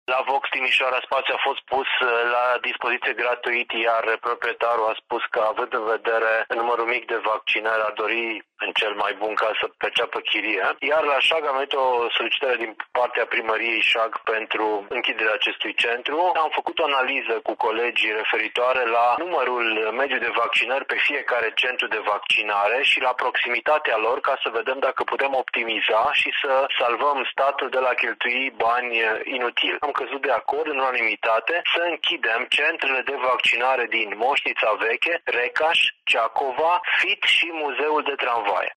Trei dintre aceste centrele se află în Timișoara, respectiv VOX, Casa Tineretului și Muzeul de Tramvaie, iar restul în localitățile Șag, Moșnița Veche, Recaș și Ciacova, spune prefectul Mihai Ritivoiu.
Între centrele de vaccinare care se vor închide se numără și cel pentru copiii cu vârste cuprinse între 5 și 11 ani deschis la Casa Tineretului în urmă cu doar două săptămâni, explică prefectul de Timiș.